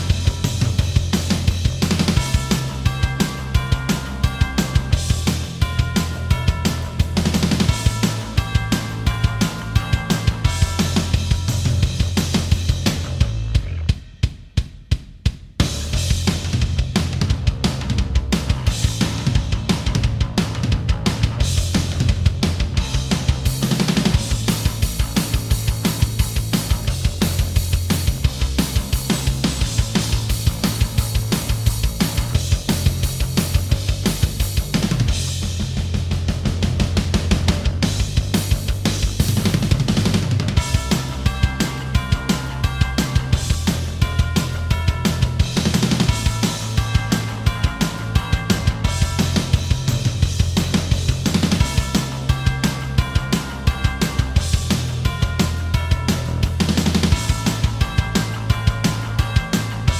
Minus Main Guitars For Guitarists 3:18 Buy £1.50